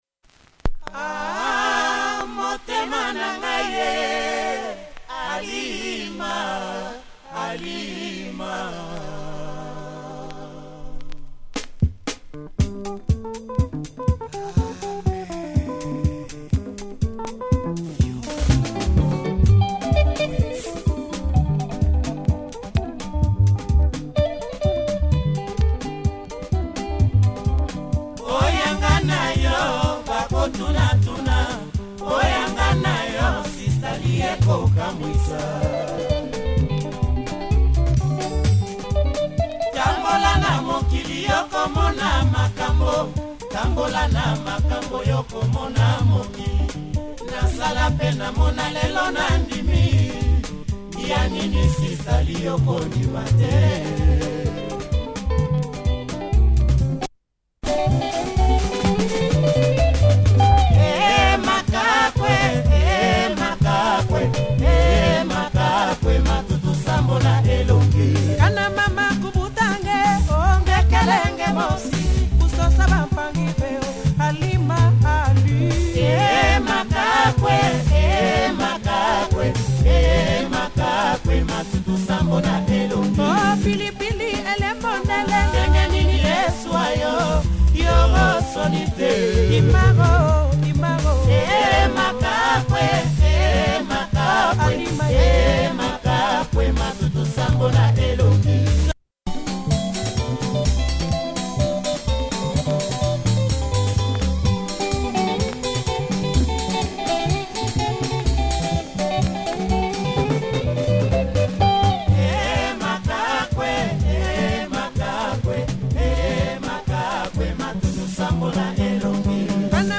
Nice Lingala